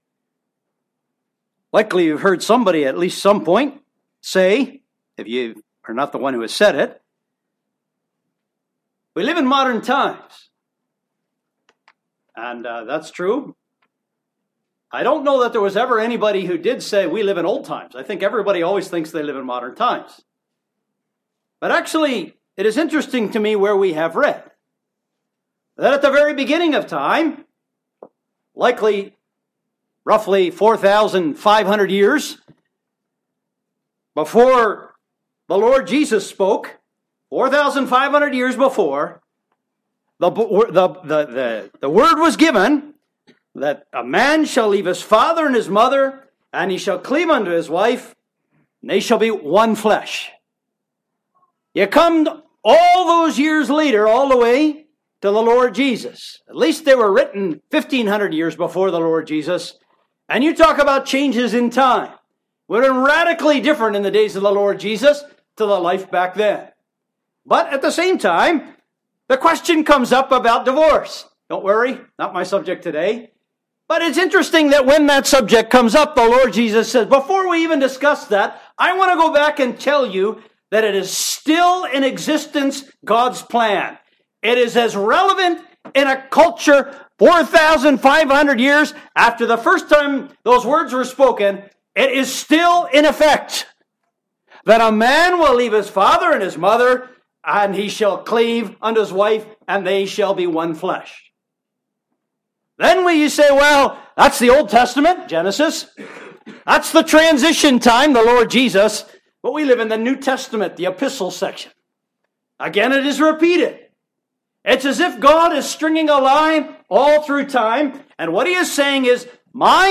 (Recorded at the Hickory Gospel Hall conference, NC, USA, 2019)